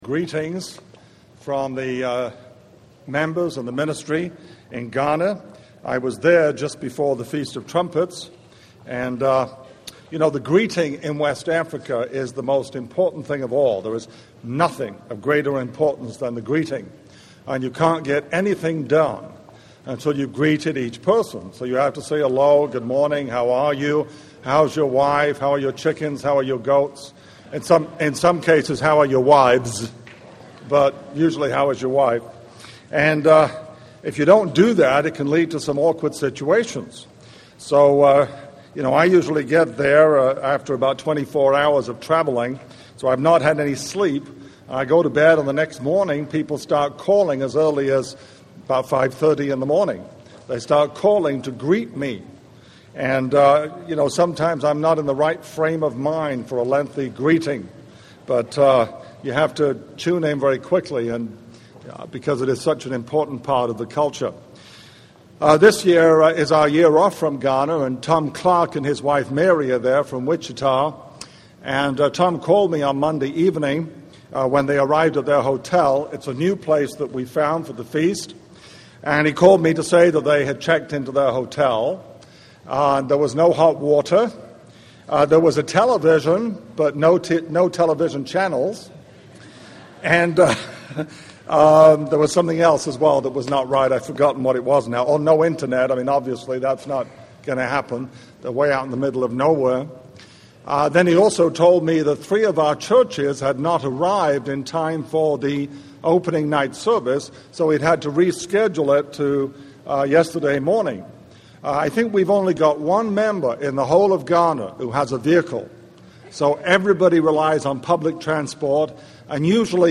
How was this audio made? This sermon was given at the Wisconsin Dells, Wisconsin 2008 Feast site.